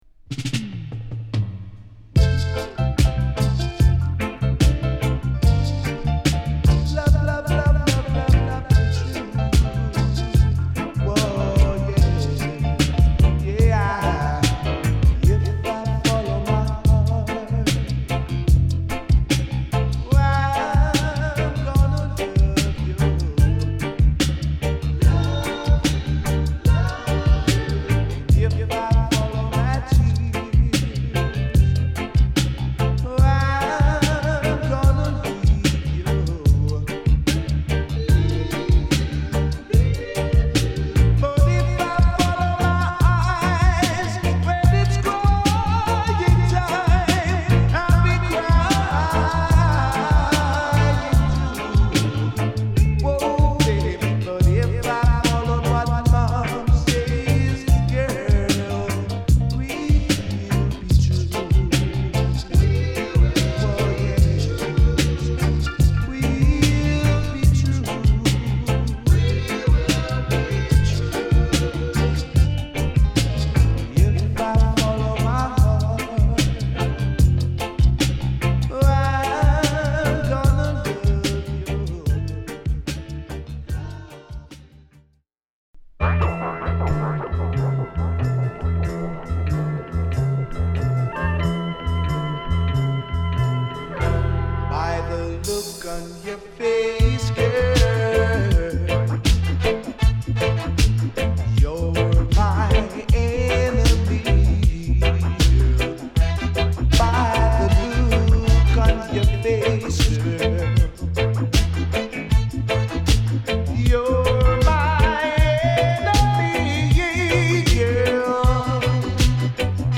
＊試聴はB4→B5→A1→A4です。